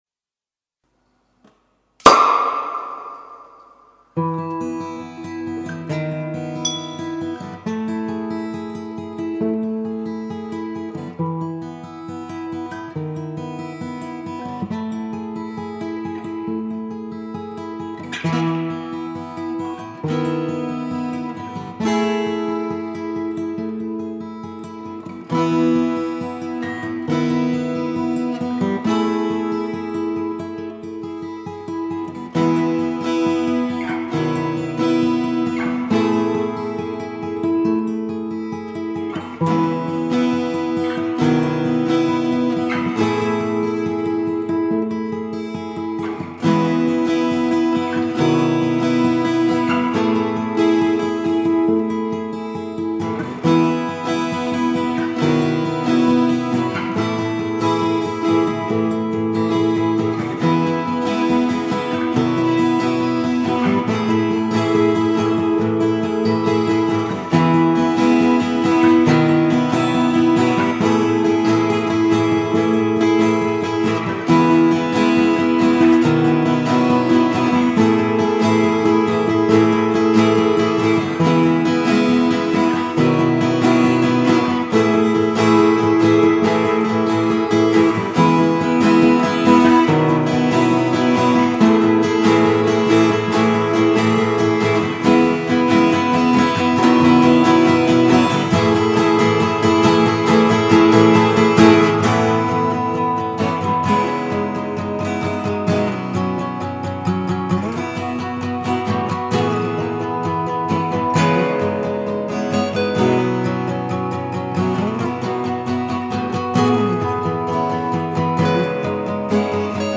drei Gitarren, ein Mikrofon und né Kiste Flens...flensburg.mp3